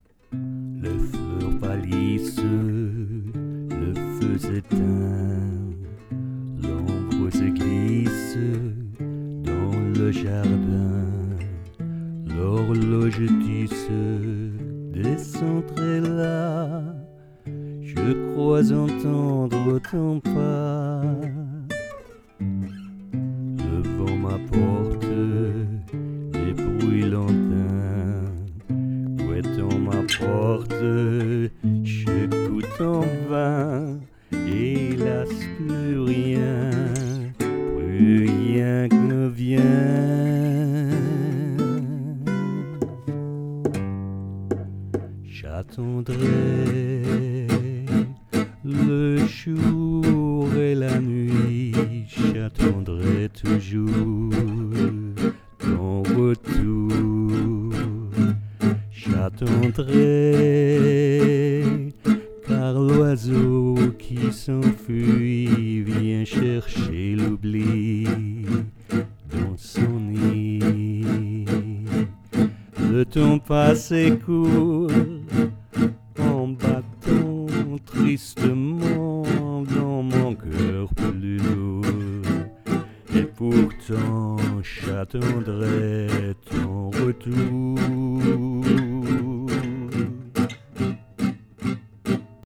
Here is a vocal demo.
But I think there should be more "acting" the emotion, which is difficult to record, because I was very very close to the mic, with low voice and low energy, almost with my speaking voice.
Stanza 1 and verse Demo.WAV
stanza-1-and-verse-demo-wav.wav